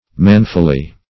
Man"ful*ly, adv. -- Man"ful*ness, n.